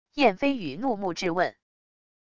燕飞雨怒目质问wav音频